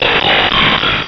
Cri de Registeel dans Pokémon Rubis et Saphir.